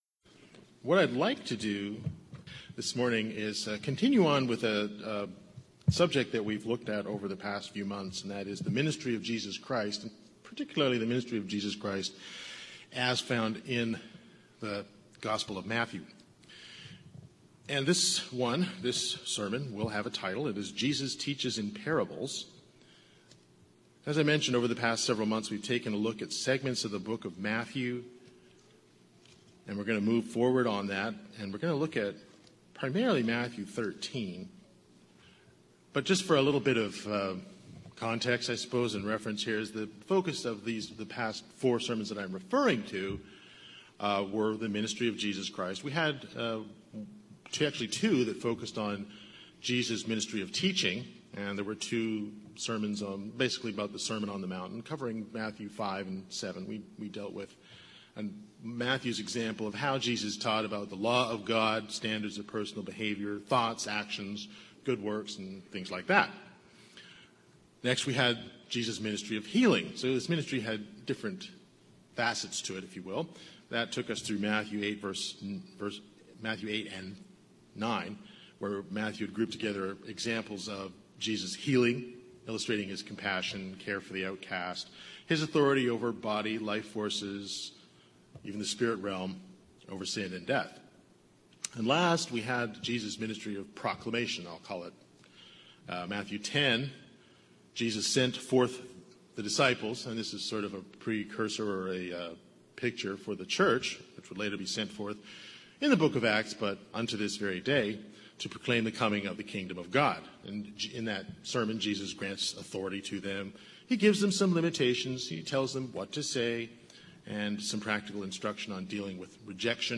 The focus of the past 4 sermons from Matthew has been the ministry of Jesus…